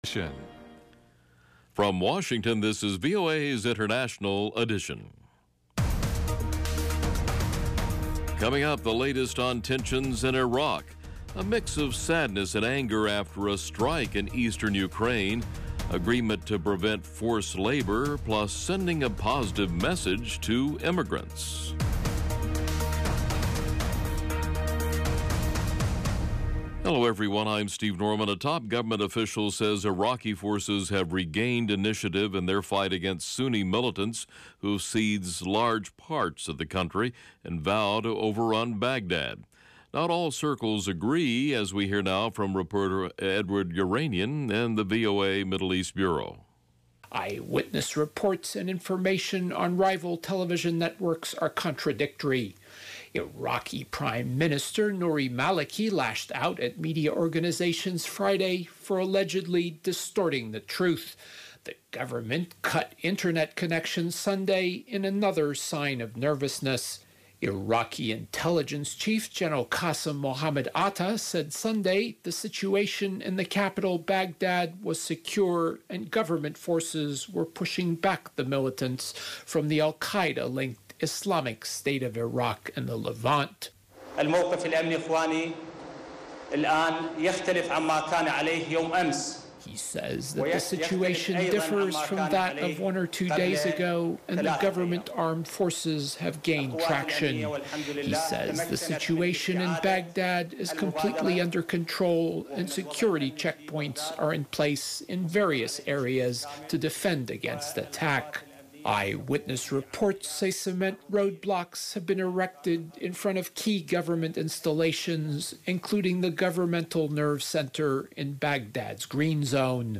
International Edition gives you 30 minutes of in-depth world news reported by VOA’s worldwide corps of correspondents - on the events people are talking about.